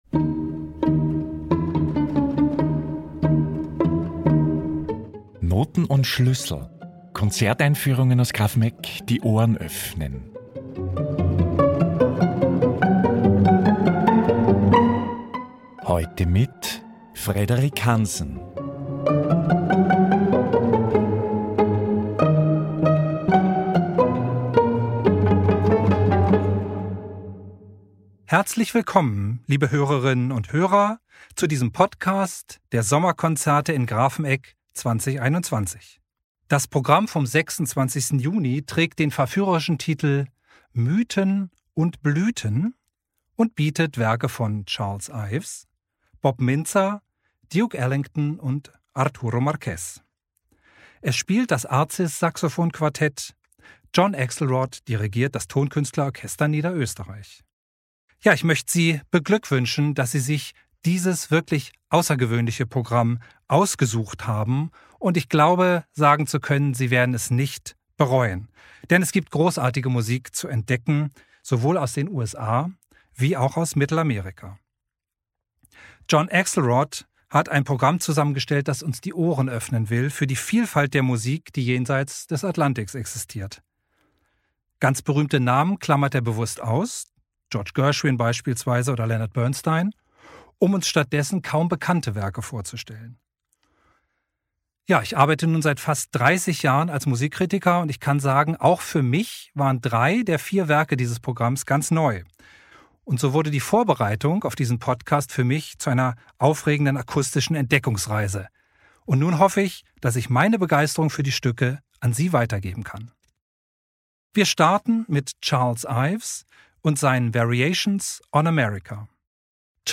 «NOTEN & SCHLÜSSEL» #1 | Konzerteinführung 26. Juni 2021 | Mythen & Blüten ~ Grafenegg Podcast